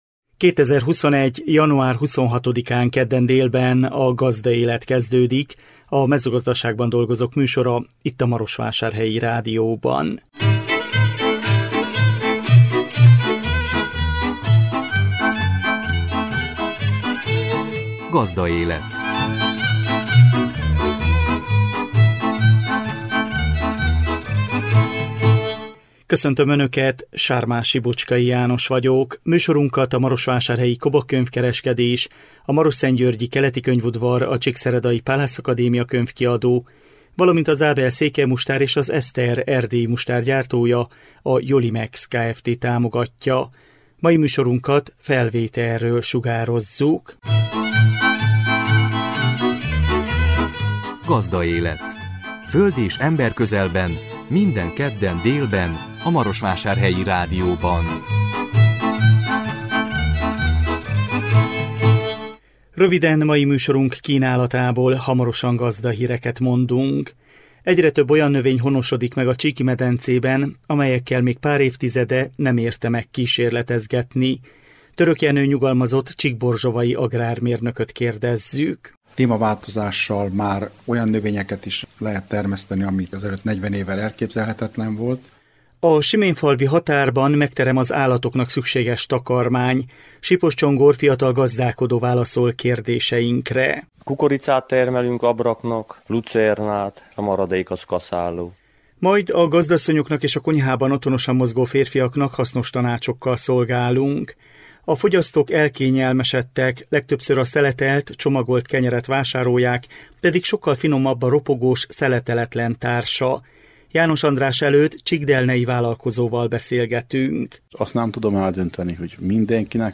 GAZDAÉLET - Becsüljük meg értékeinket - Marosvasarhelyi Radio